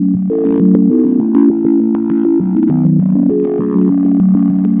大海和海鸥
描述：大海和海鸥的声音，很美~~
标签： 大海 海鸥 海边 浪花
声道立体声